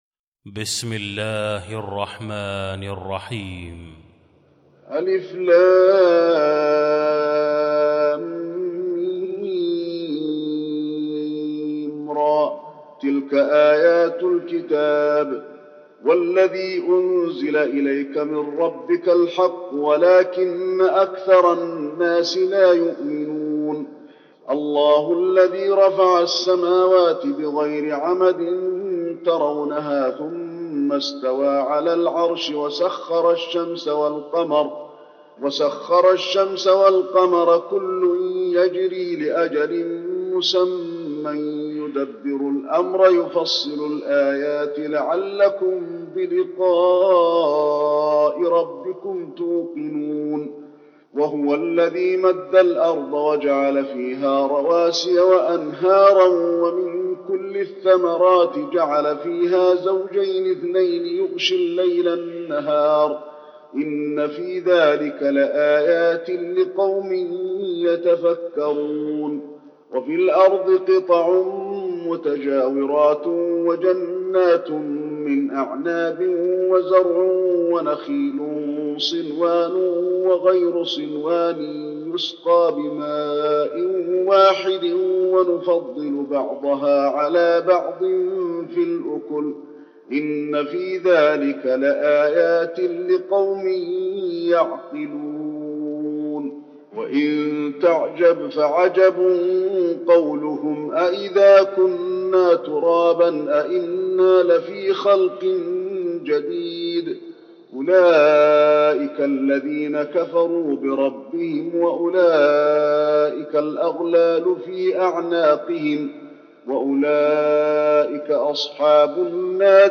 المكان: المسجد النبوي الرعد